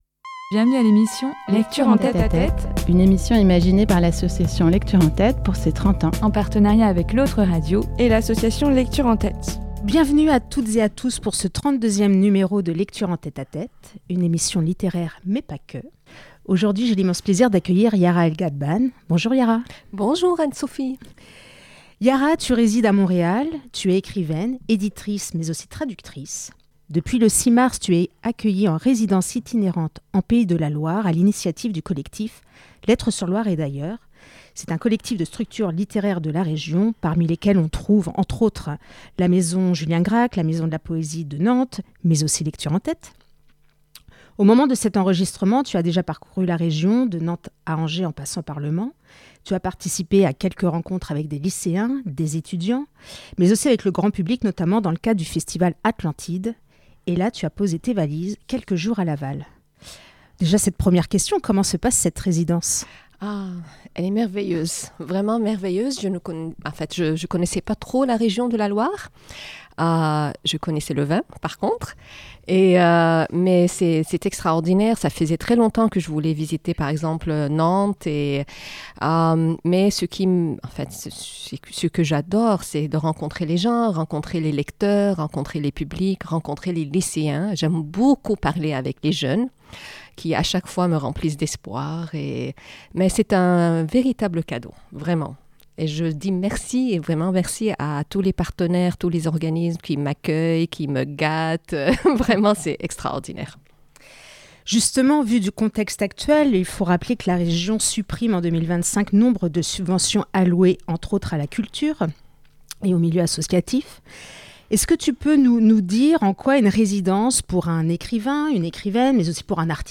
Un échange lumineux, empli d'espoir et de générosité.